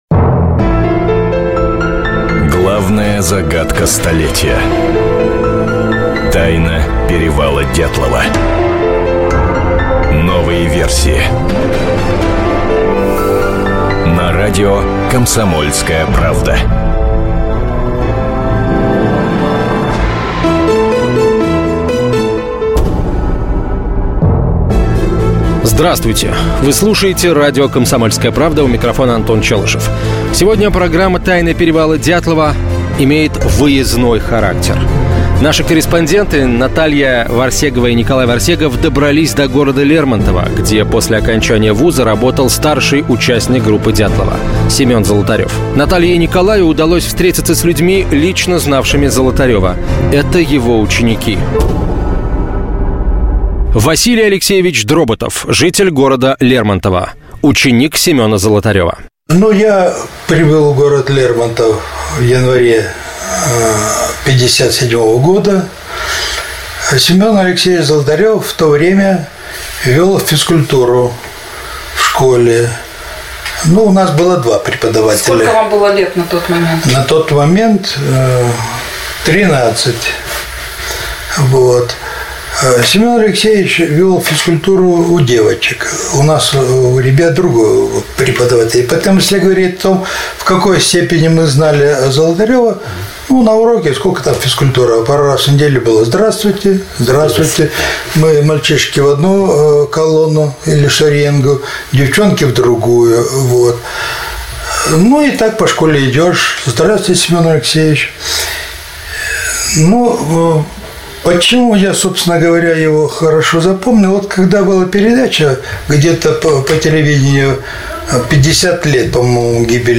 Аудиокнига Продолжение расследования: Ученики Золотарева | Библиотека аудиокниг